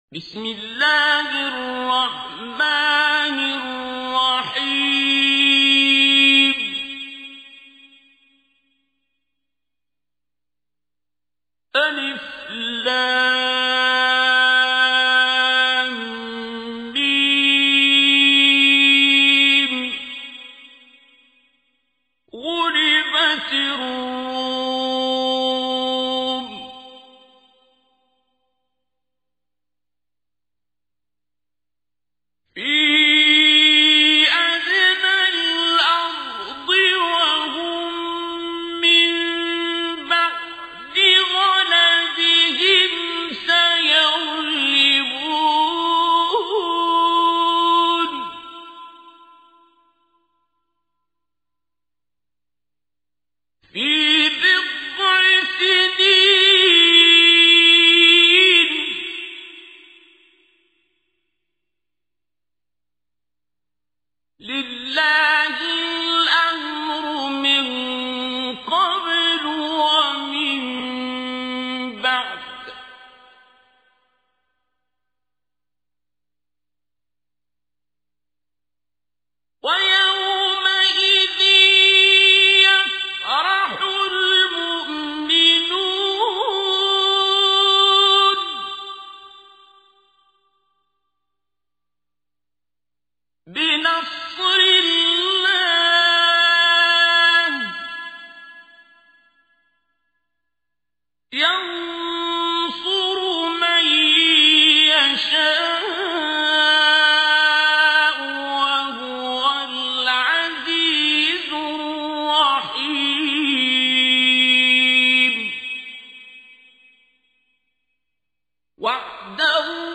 تحميل : 30. سورة الروم / القارئ عبد الباسط عبد الصمد / القرآن الكريم / موقع يا حسين